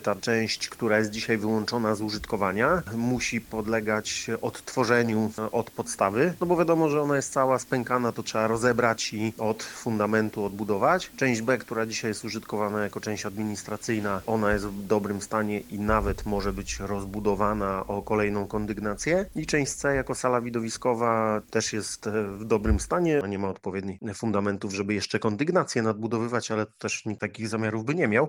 Dla Twojego Radia temat szerzej przedstawia wiceburmistrz Goleniowa Piotr Wolny